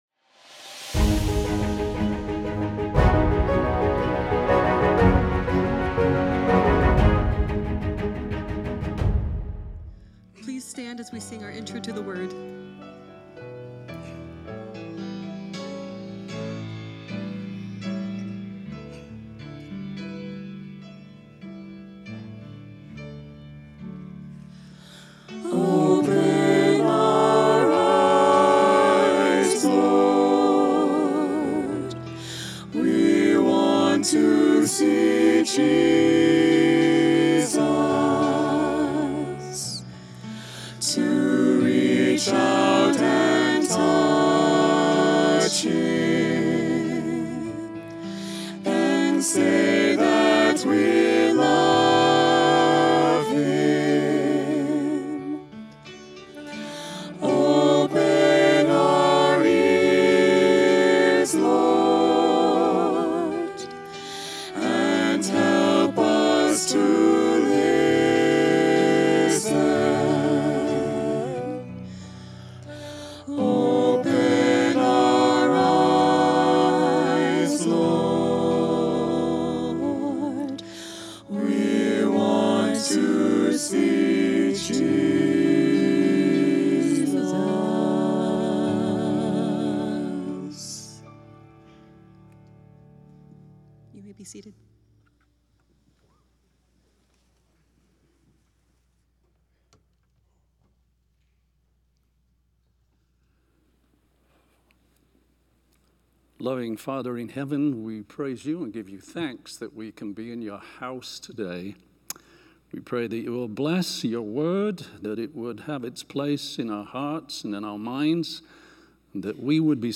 From Series: "Central Sermons"